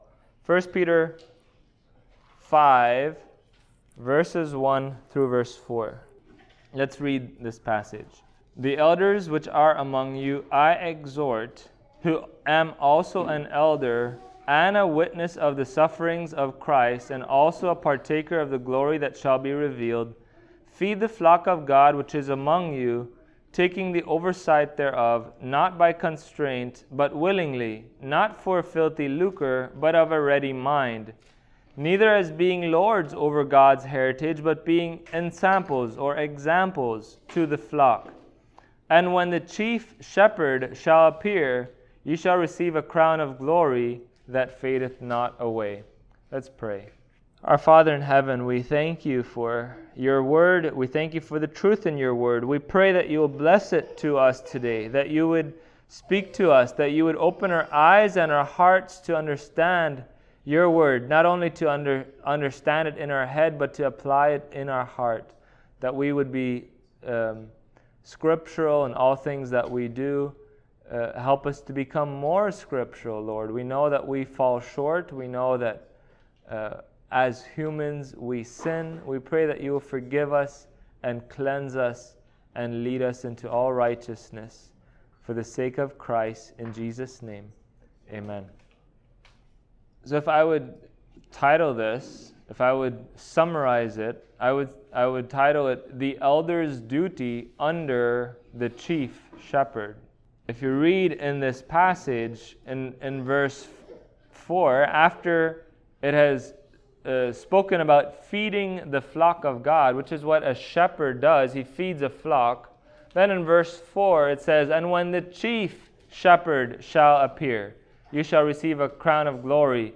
Passage: 1 Peter 5:1-4 Service Type: Sunday Morning